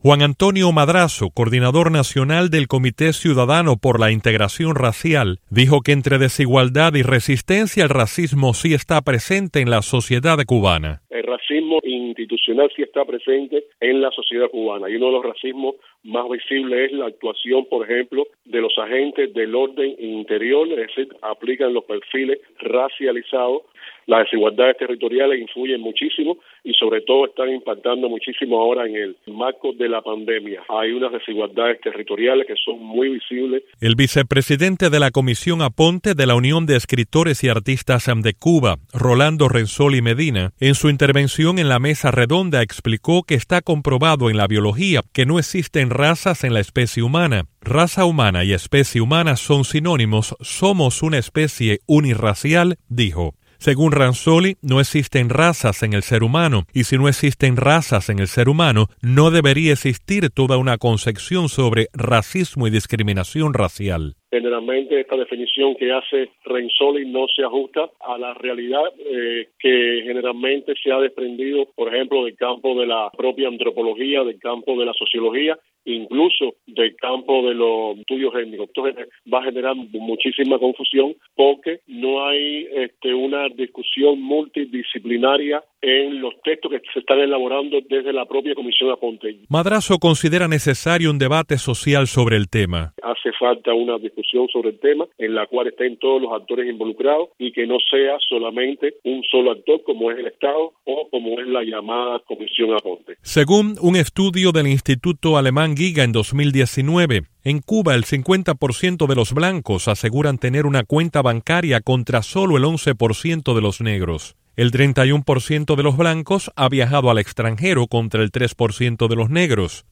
En entrevista con Radio Televisión Martí
Declaraciones de activista por la integración racial